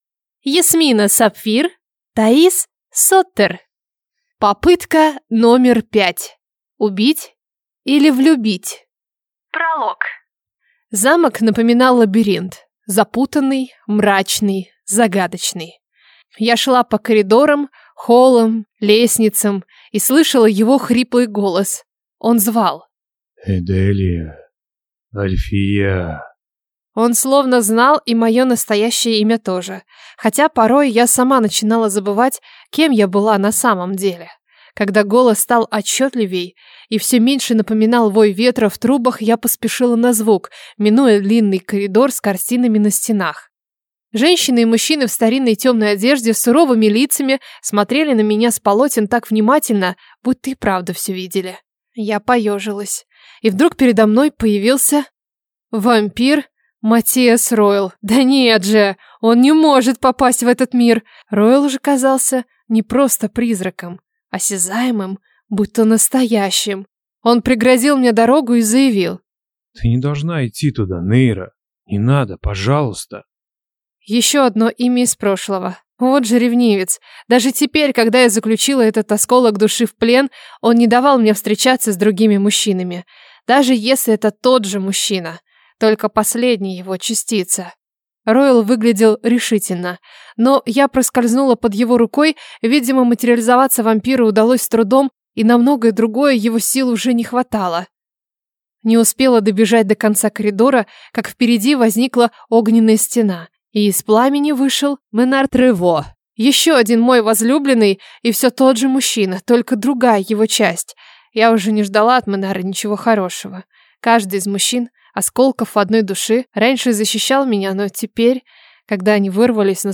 Аудиокнига Попытка номер пять. Убить или влюбить | Библиотека аудиокниг